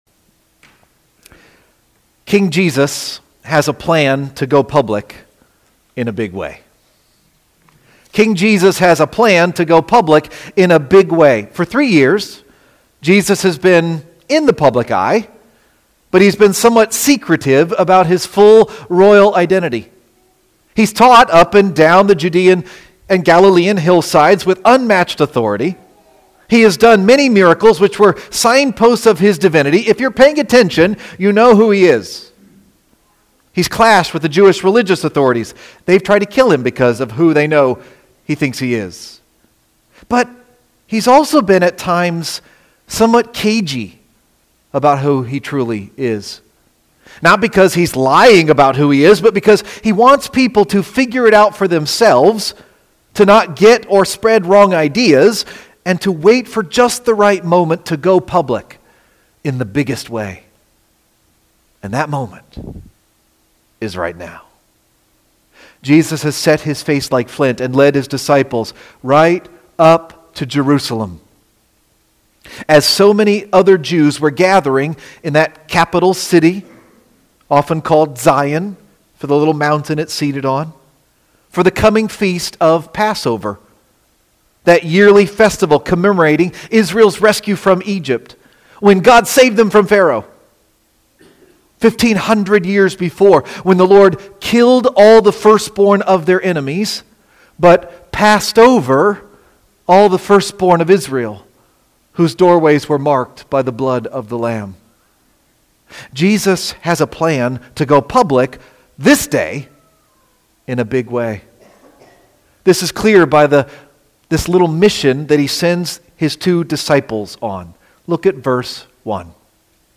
You can listen here or “Right-Click” and “Save-as” to download the sermon: “Your King Comes To You”– March 29, 2026